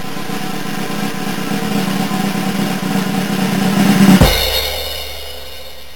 Drum Roll